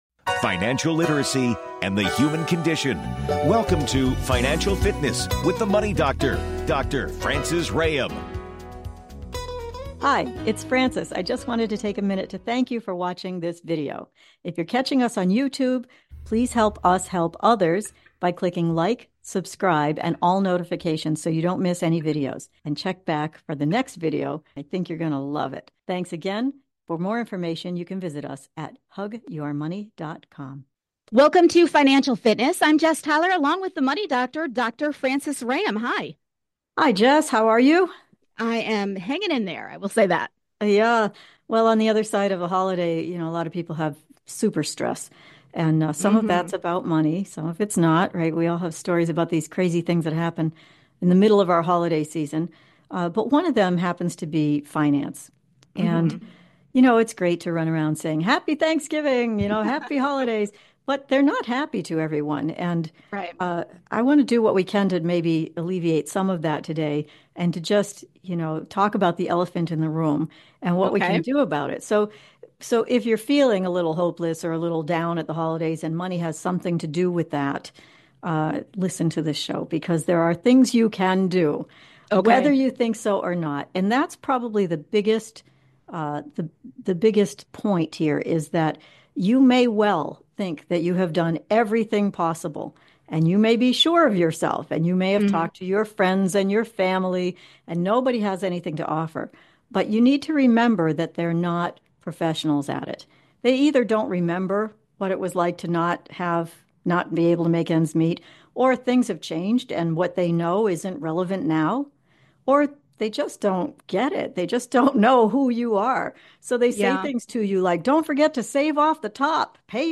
Talk Show Episode, Audio Podcast, Financial Fitness with The Money Doctor and Its Never Hopeless on , show guests , about hopelessness,financial freedom,financial success,the money doctor,financial recovery, categorized as Business,Careers,Investing and Finance,Marketing,Management,Education,Emotional Health and Freedom,Self Help,Society and Culture